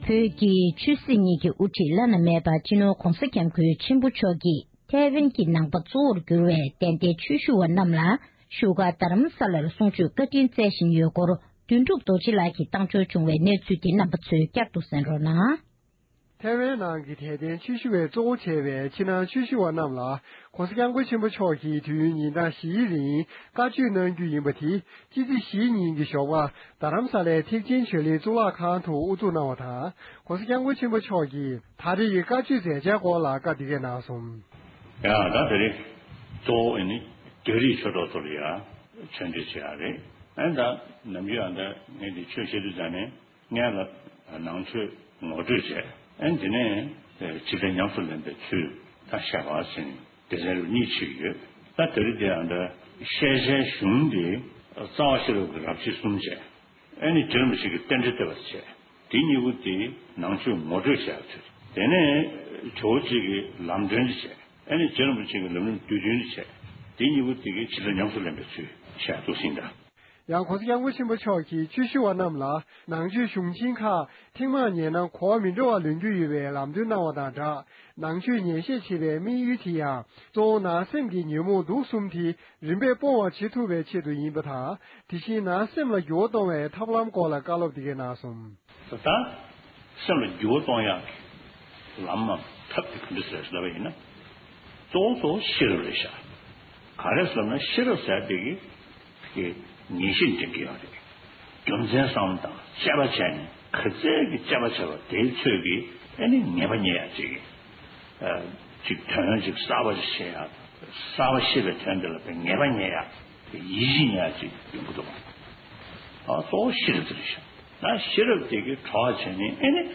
གནས་ཚུལ་ཕྱོགས་བསྒྲིགས